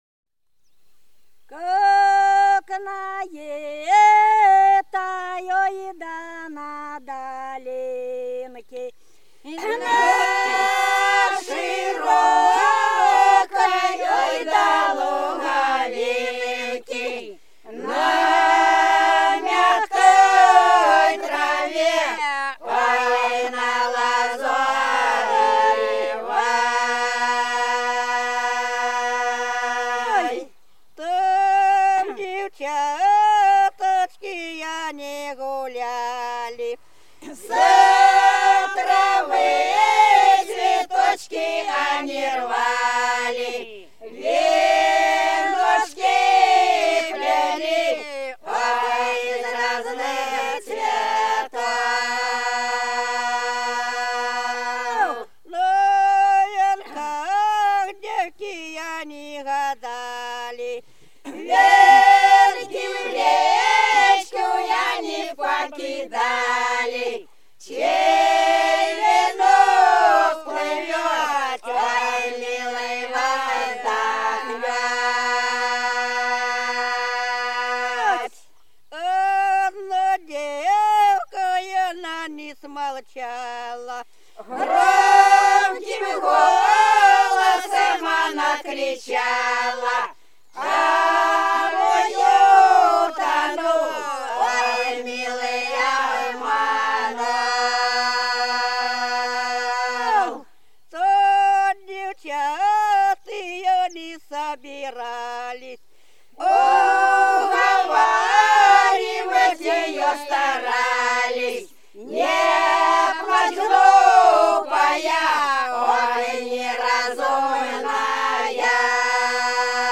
За речкою диво Как на этой долинке - протяжная (с. Пузево)
02_Как_на_этой_долинке_-_протяжная.mp3